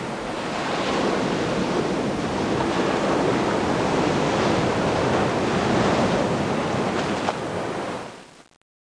1 channel
waves4.mp3